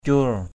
/cu:r/ (d.) vôi = chaux. ataong cur a_t” c~R quét vôi.